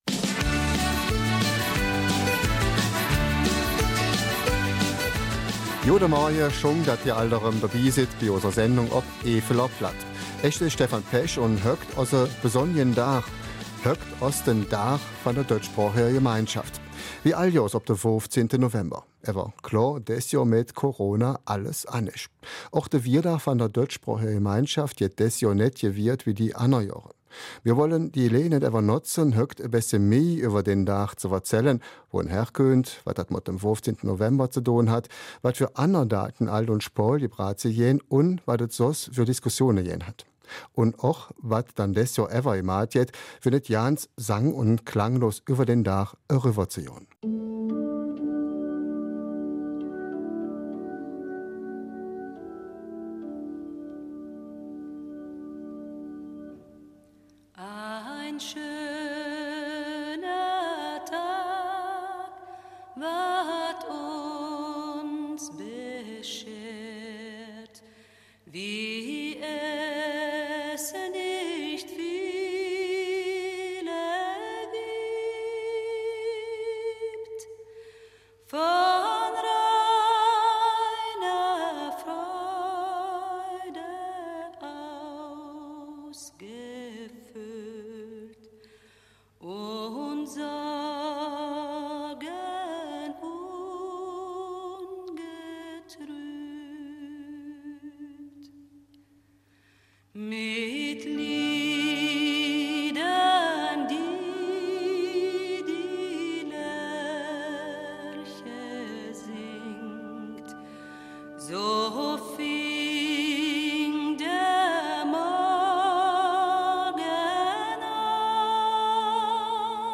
Eifeler Mundart: Tag der Deutschsprachigen Gemeinschaft